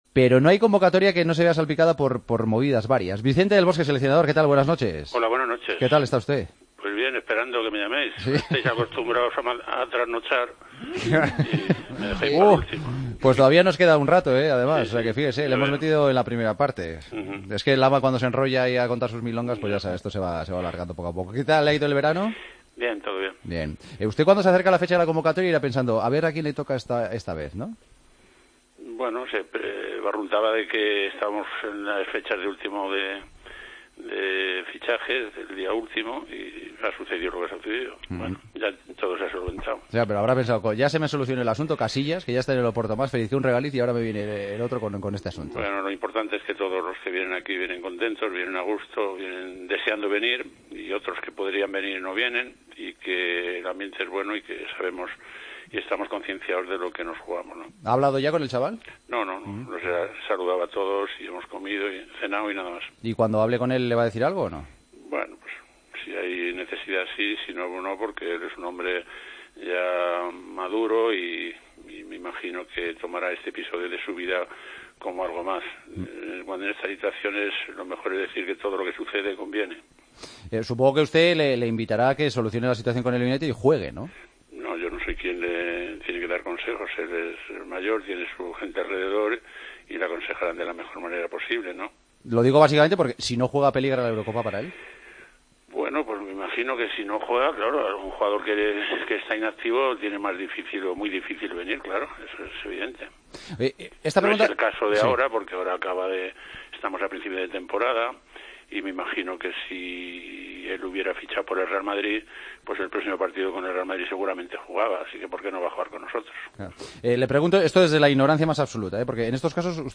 Entrevista a Vicente del Bosque en El Partido de las 12